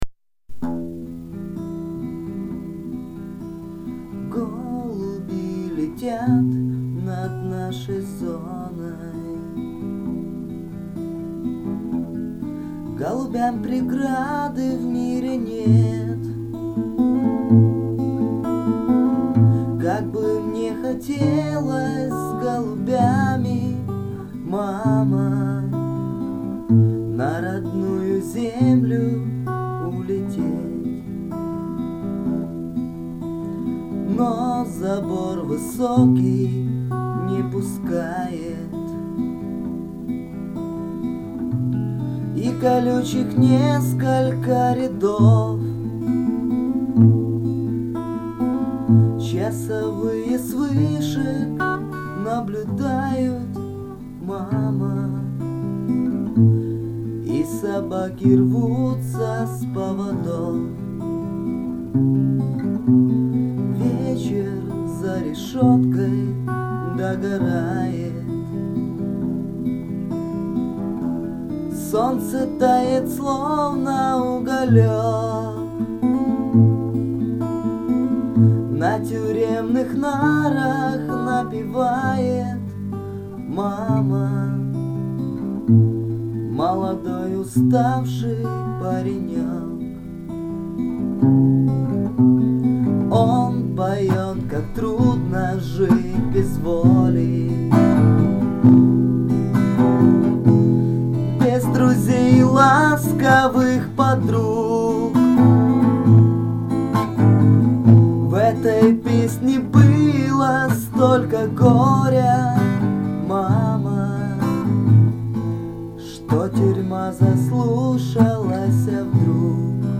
Гитара / Дворовые